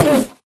mob / endermen / hit1.ogg
hit1.ogg